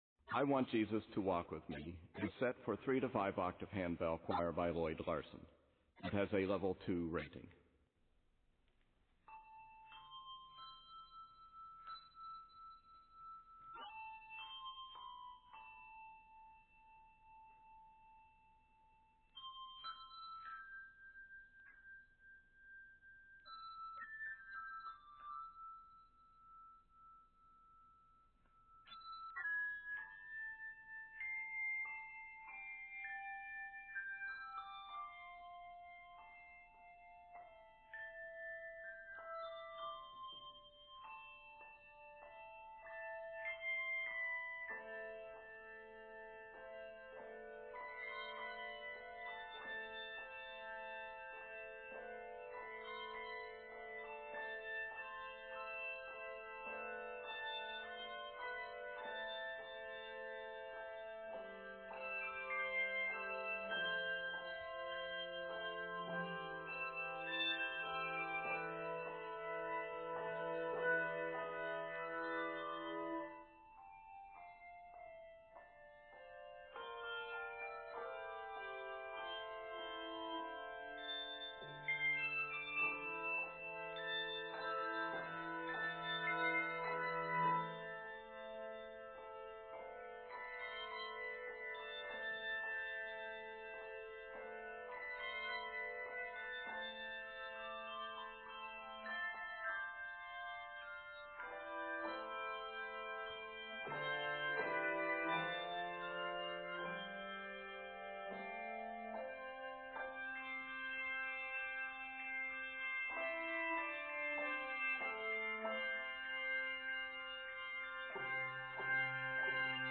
Traditional Spiritual Arranger
Octaves: 3-5